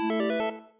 connected.ogg